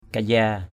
/ka-za:/